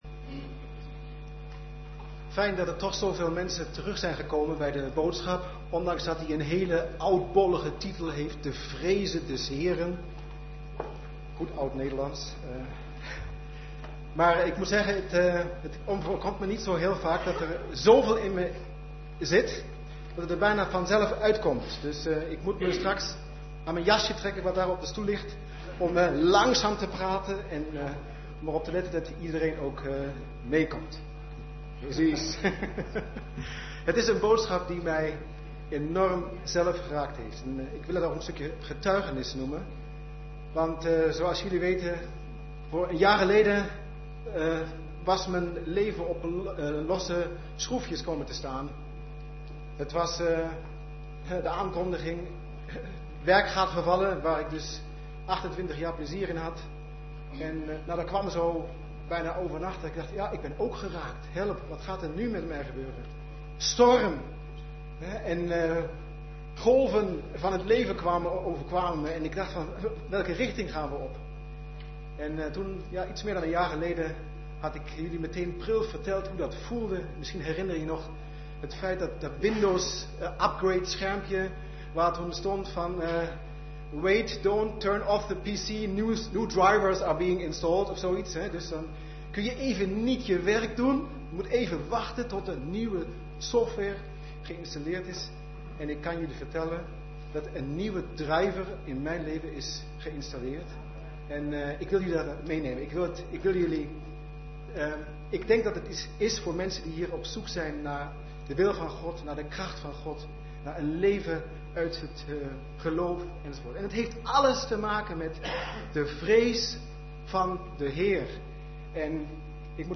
Toespraak van 10 februari: Geen gebrek hebben, wie de Heere...kennen - De Bron Eindhoven